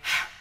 MB Vox (17).wav